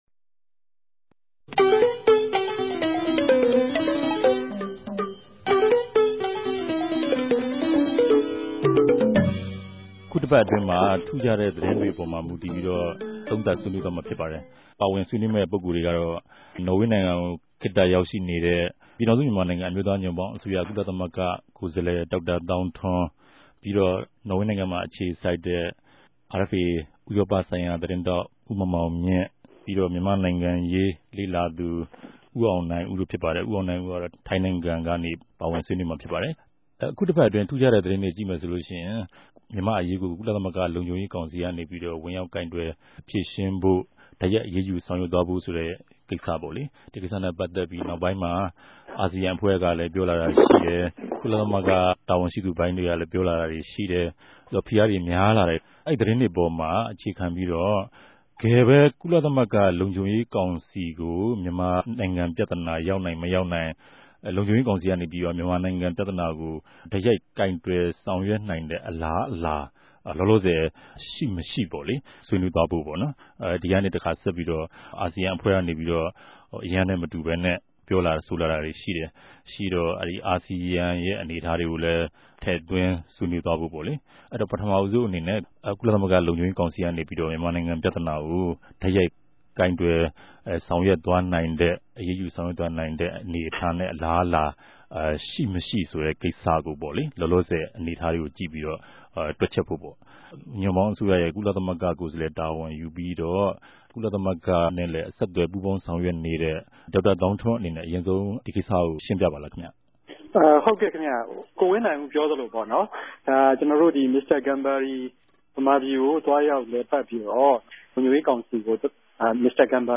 တပတ်အတြင်း သတင်းသုံးသပ်ခဵက် စကားဝိုင်း (၂၀၀၆၊ ဇြန်လ ၂၄)